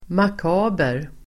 Uttal: [mak'a:ber]